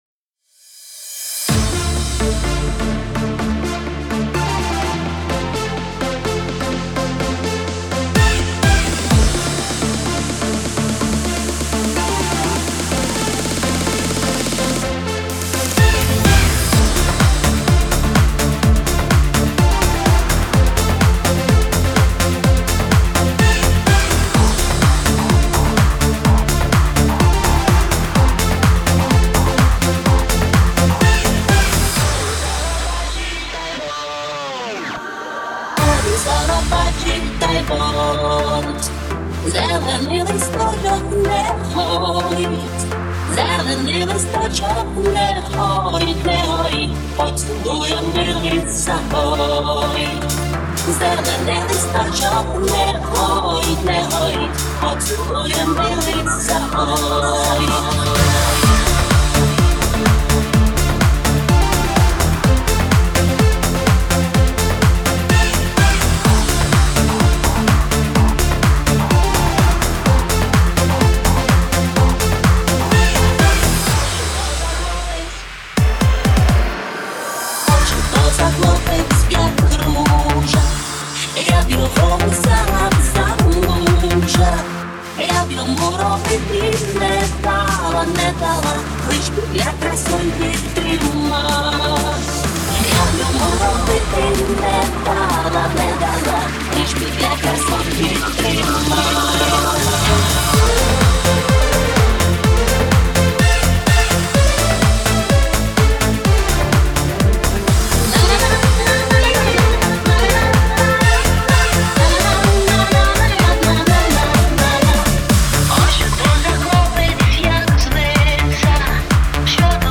Тональність-Ре_мінор( Dm).Темп-126(BPM)
Всі мінусовки жанру Remix